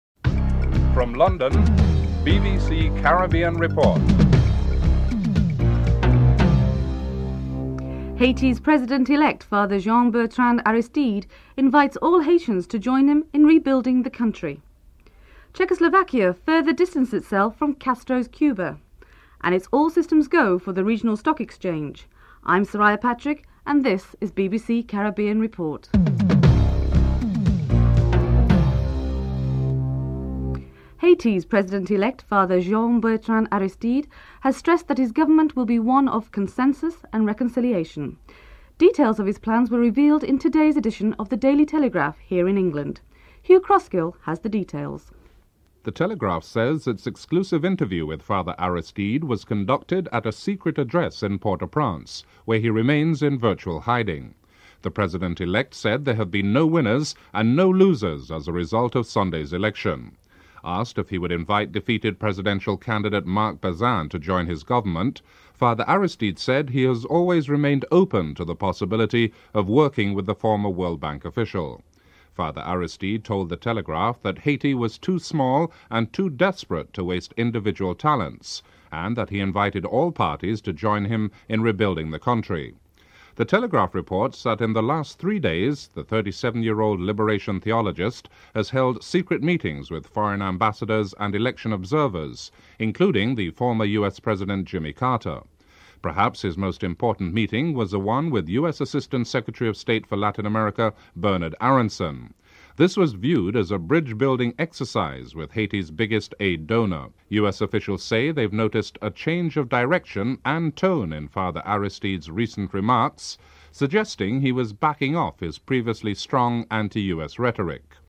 1. Headlines (00:00-00:30)
3. Czechoslovakia further distances itself from Castro’s Cuba by ceasing to represent Cuban interest at its diplomatic missions in the United States. Report from Prague (05:17-07:20)